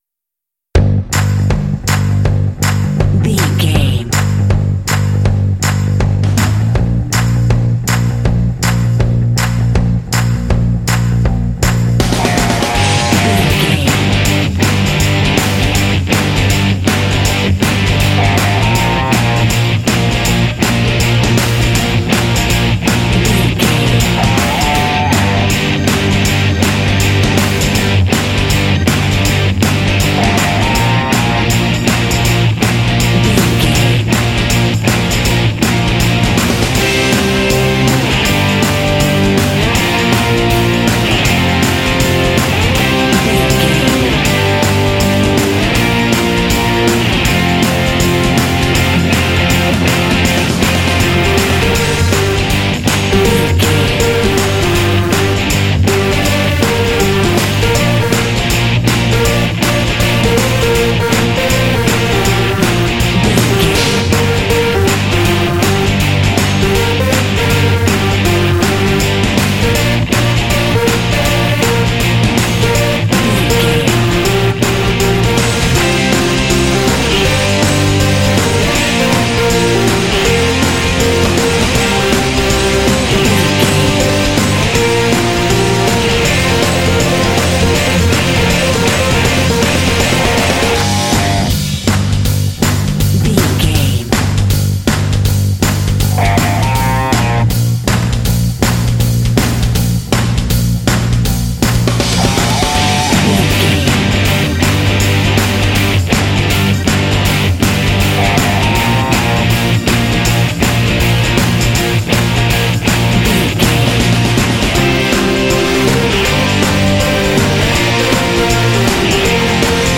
Uplifting
Aeolian/Minor
driving
powerful
energetic
groovy
positive
drum machine
electric guitar
synthesiser
bass guitar
indie
pop